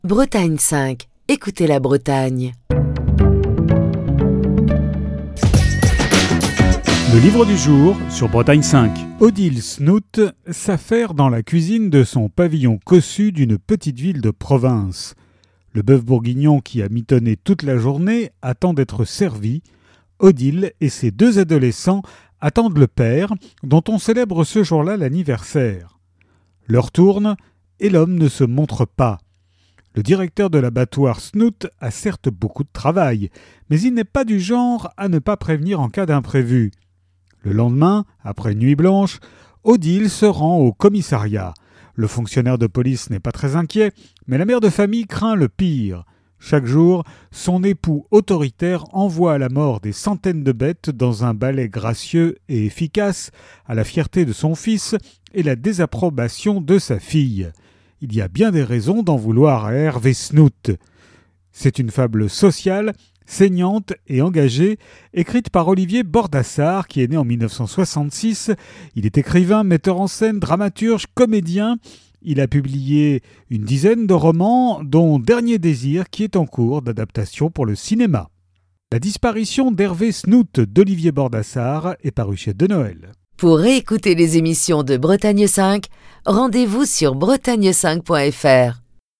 Chronique du 12 février 2024.